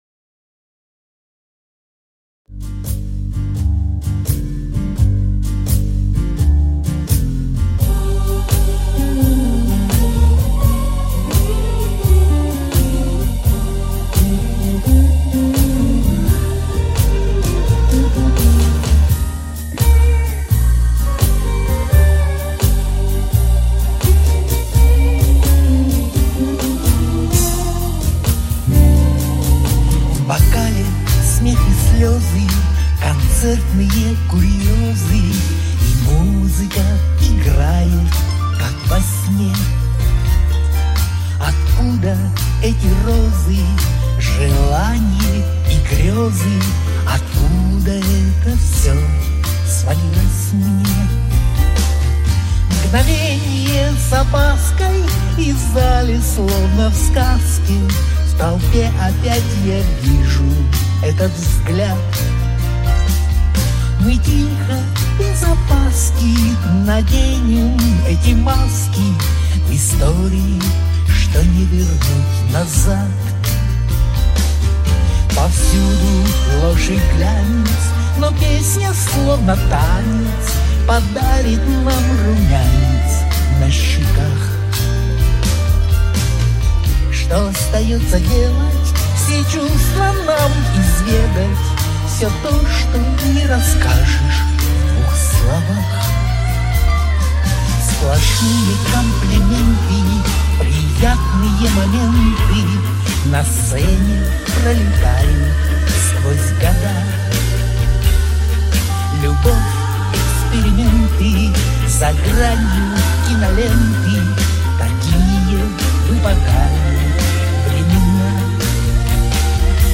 Подскажите пож. в чем моя ошибка? кроме того что пою я плохо...чую что то не так...а почему?